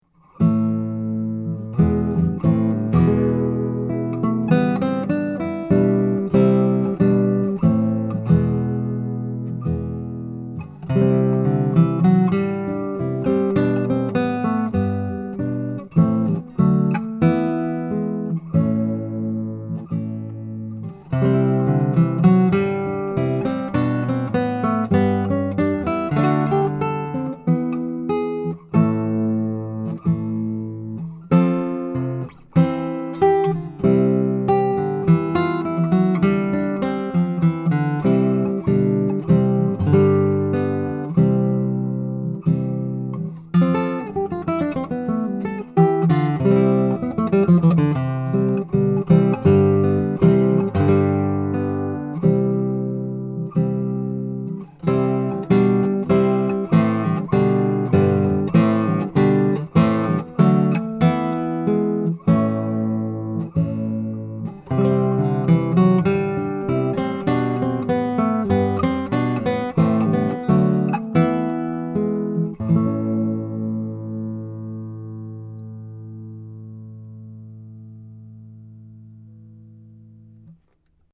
VINTAGE Neumann Km84 Condenser Mic KM-84 / KK84
* Polar Pattern: Cardioid
The grill has a thread issue wich makes exchanginging capsules more difficult, but the original KK84 capsule is properly mounted, and the mic sounds wonderful, with higher output compared to other KM84s I've owned.
MP3 clip of it on a 6-string that I have for sale.